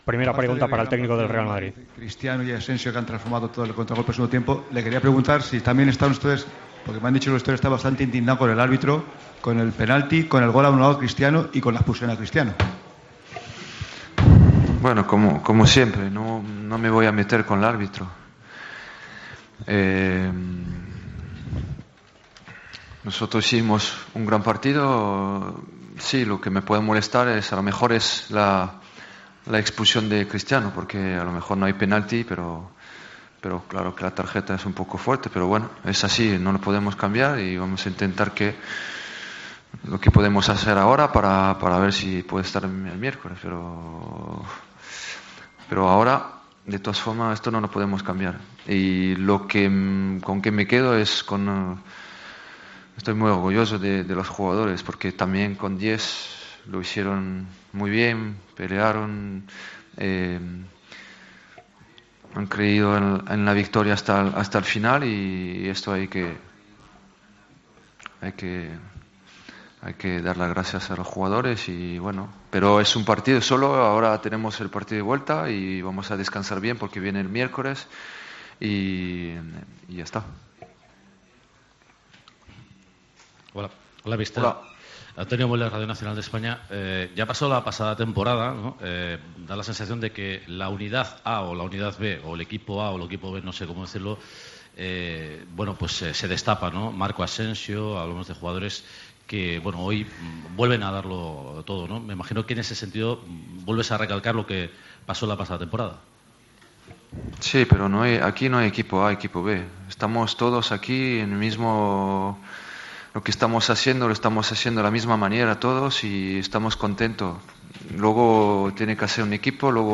El entrenador del Real Madrid analizó la victoria ante el Barcelona: "No me voy a meter con el árbitro. Hicimos un gran partido. Me puede molestar la expulsión de Cristiano. La tarjeta es un poco fuerte. Pero no podemos cambiar nada. No hay equipo A y B; aquí cumplen todos. Asensio hace la diferencia cada vez que juega"